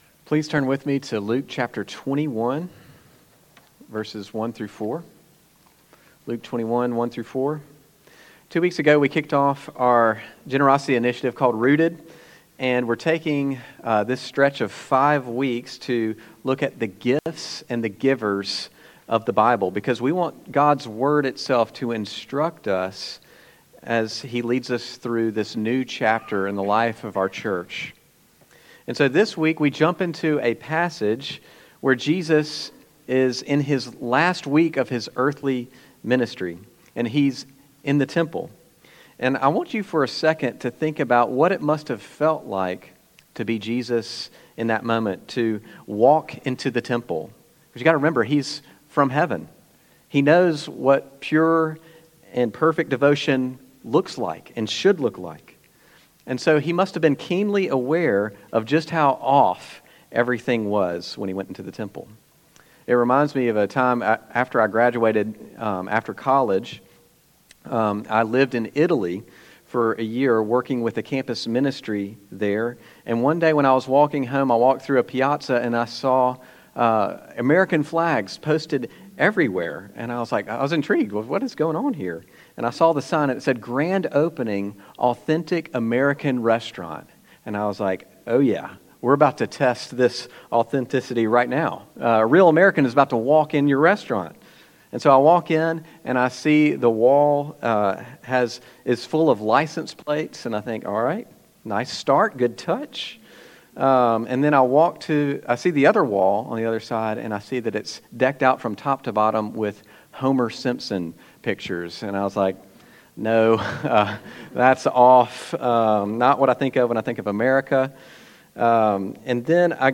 2025 How Jesus Does Math Preacher